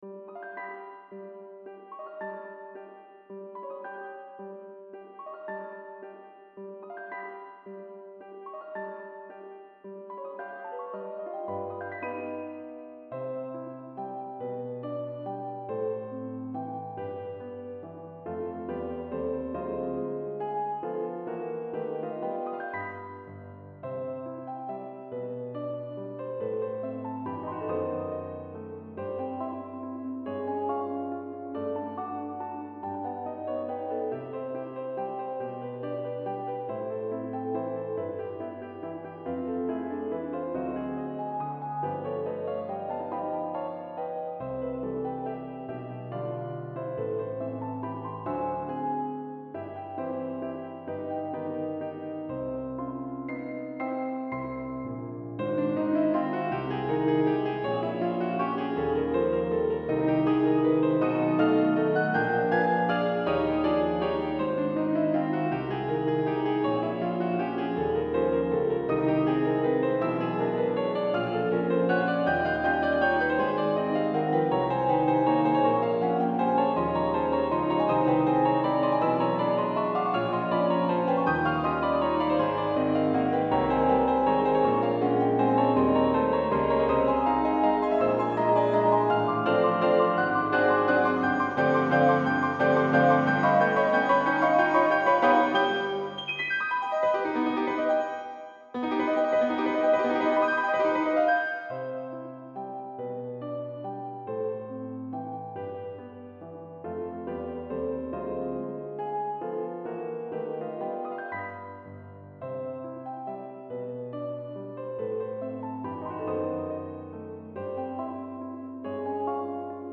Piano http